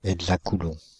Ääntäminen
Ääntäminen France (Île-de-France): IPA: /əɡ.za.ku.lɔ̃/ Haettu sana löytyi näillä lähdekielillä: ranska Käännös Substantiivit 1. eksakulombo Suku: m .